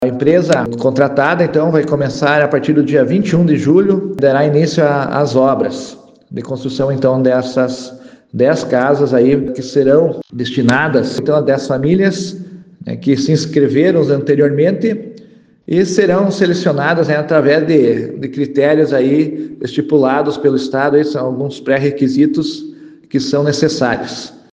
Segundo o secretário municipal de Saúde, Desenvolvimento Social, Habitação e Meio Ambiente, Cléber Denes, as edificações iniciam em breve.